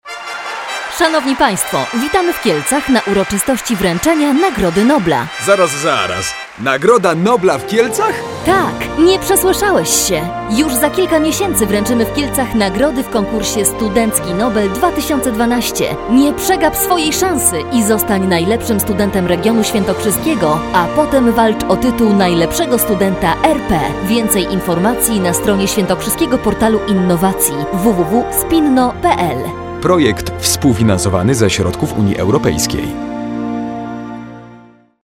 Spot radiowy "Studencki Nobel"
174-spot-radiowy-studencki-nobel